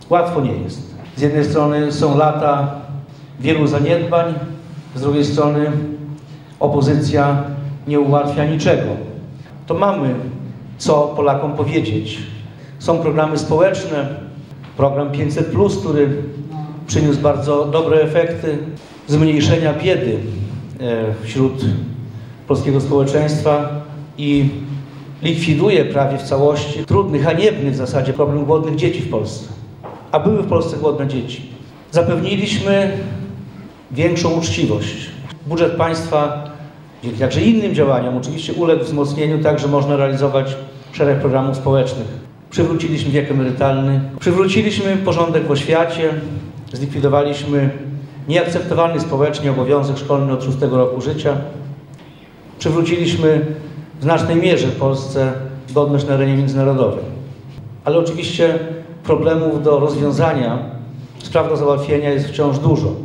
Miejscem spotkania w Suwałkach była sala Biblioteki Parafialnej przy kościele pod wezwaniem świętego Aleksandra. – Opozycja nie ułatwia nam niczego. Mimo to są pozytywne efekty naszej pracy – mówił obecny na spotkaniu Jarosław Zieliński.